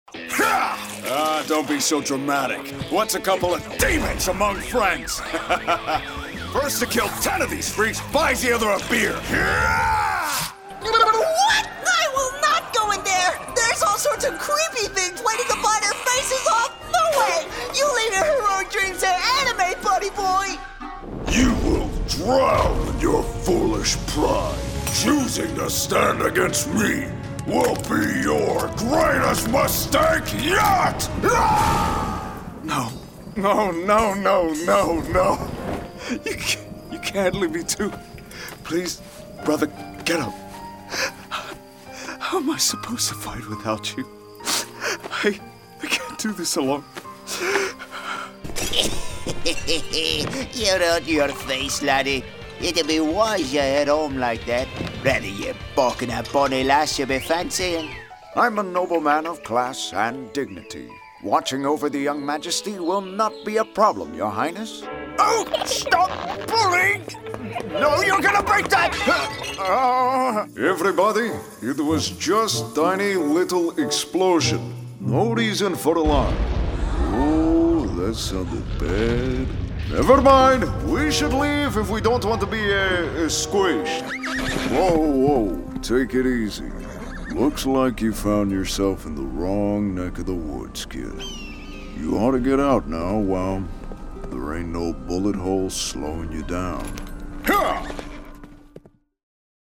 Teenager, Young Adult, Adult, Mature Adult
Has Own Studio
ANIMATION 🎬